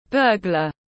Kẻ đột nhập tiếng anh gọi là burglar, phiên âm tiếng anh đọc là /ˈbɜː.ɡlər/.
Burglar /ˈbɜː.ɡlər/
Burglar.mp3